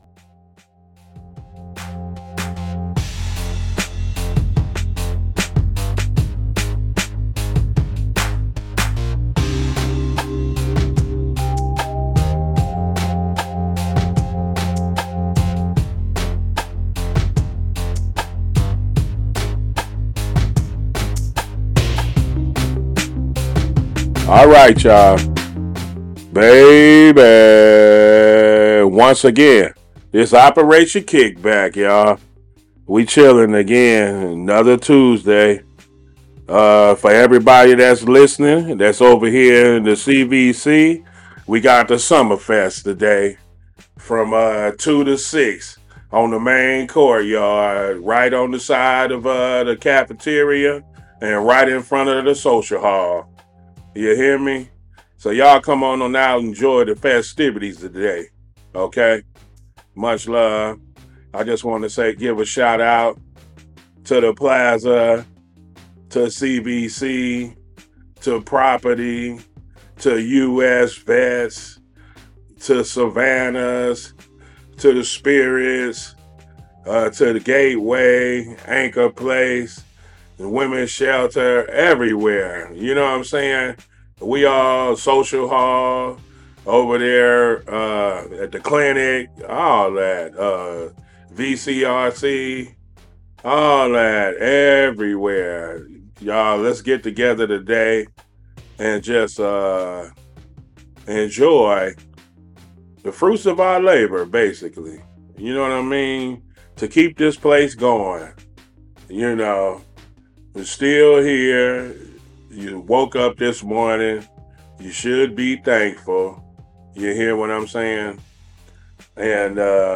This episode of Operation KickBack aired live on CityHeART Radio Tuesday 8.6.24 at 1pm.